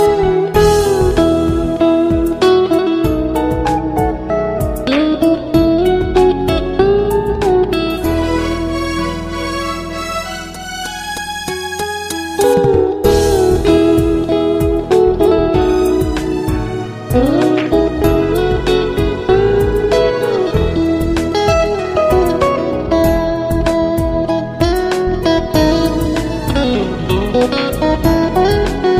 Klassik